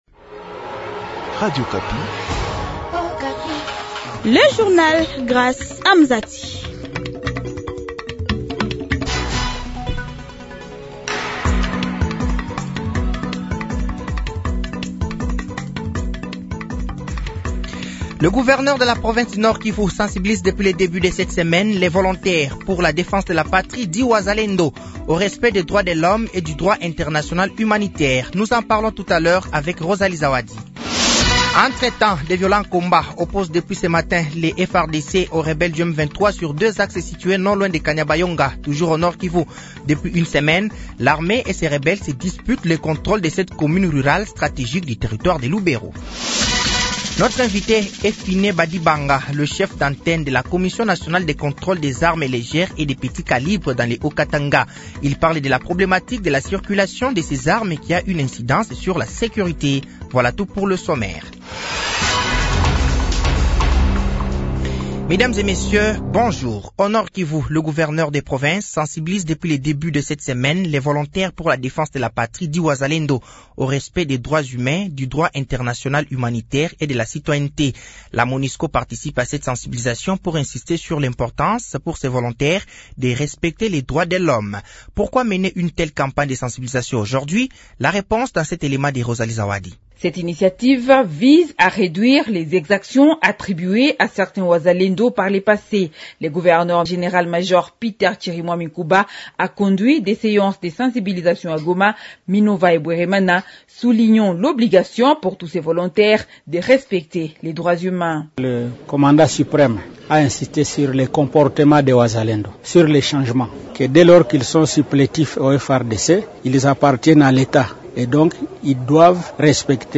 Journal français de 15h de ce dimanche 02 juin 2024